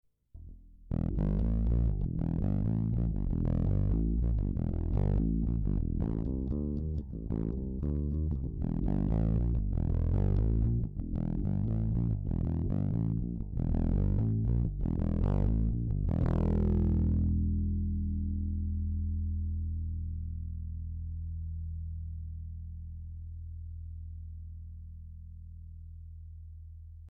『Ibanez RD400』リアハムピックアップのみを使用。
ピッキングはブリッジ近く。
イコライザはバスがフル。トレブルが０。